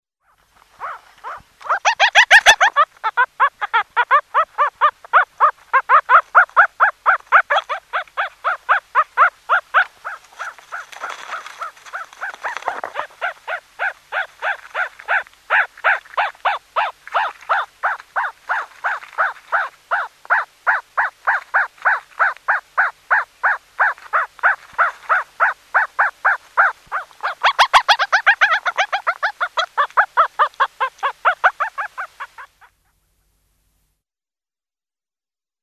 Звуки щенков
Звук, издаваемый одним щенком